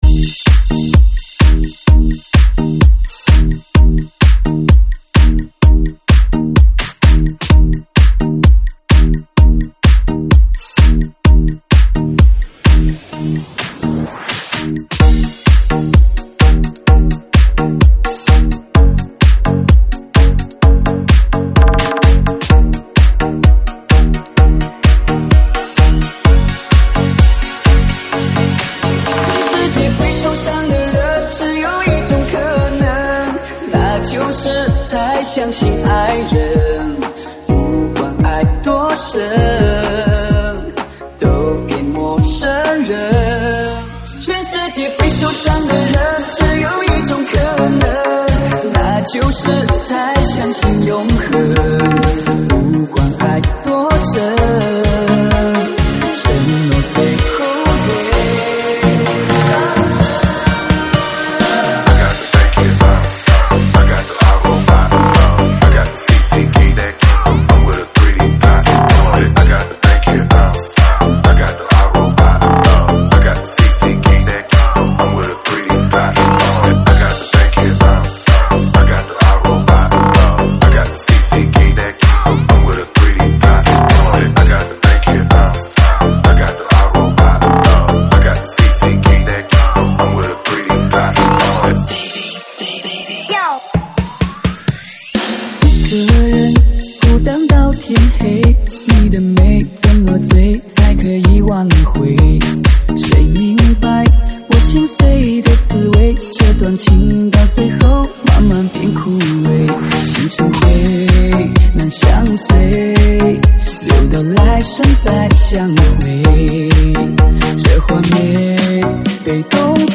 舞曲类别：中文舞曲